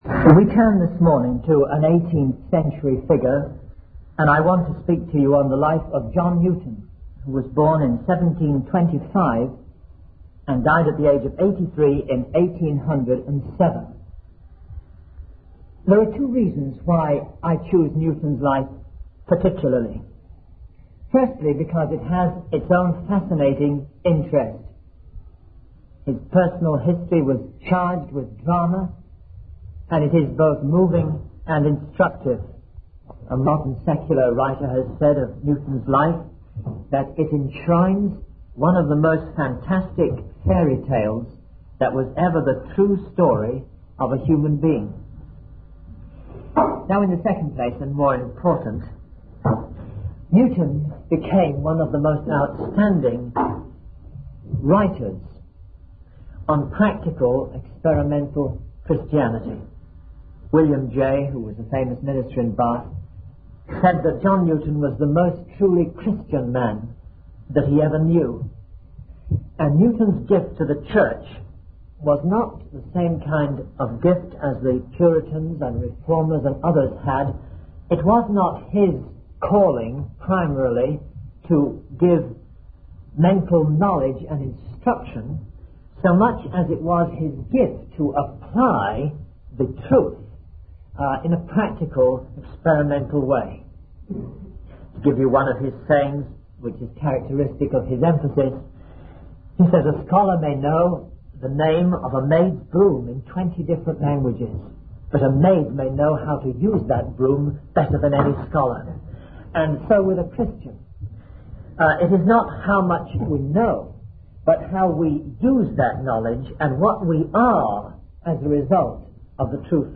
In this sermon, the speaker recounts the story of John Newton, a man who experienced a life-changing event during a storm at sea.